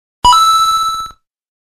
Coin.mp3